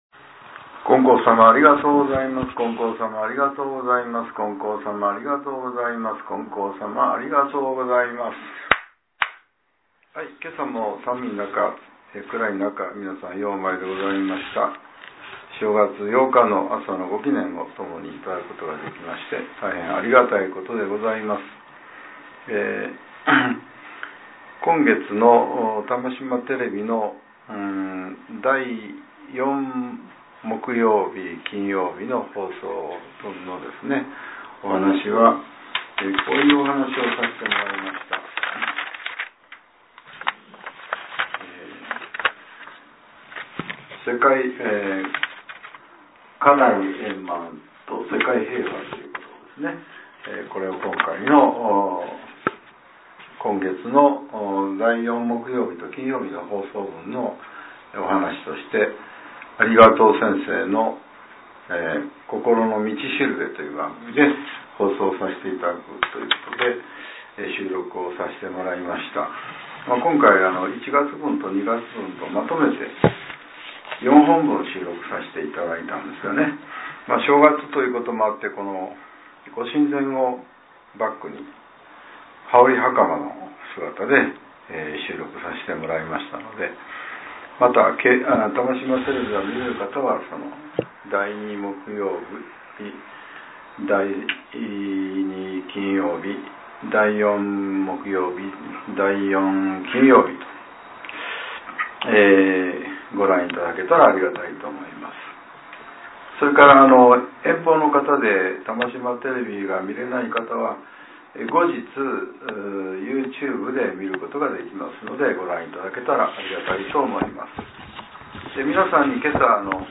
令和７年１月８日（朝）のお話が、音声ブログとして更新されています。